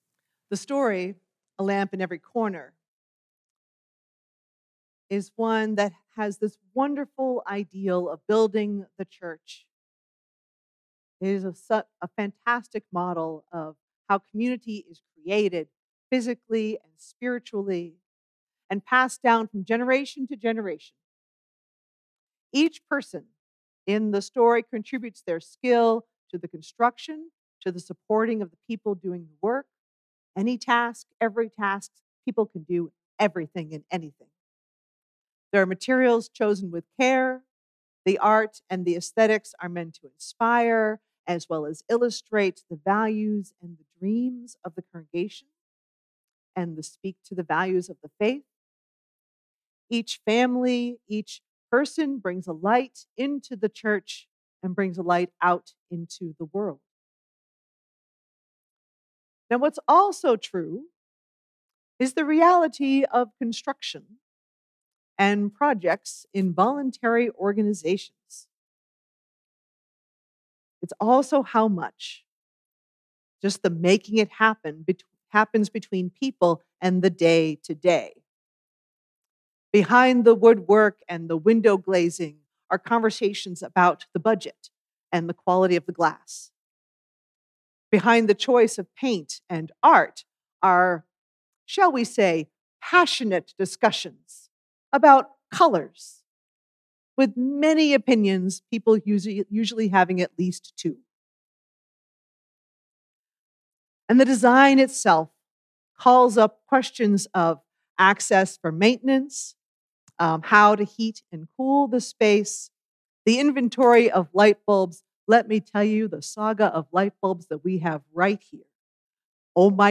Weekly sermons from the Universalist Unitarian Church of Peoria, Illinois